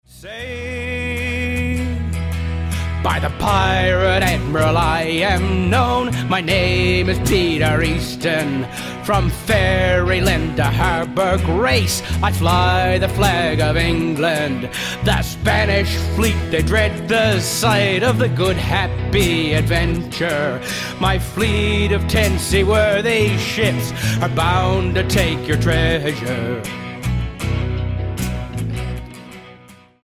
- historical piece, done in a Classic Rock style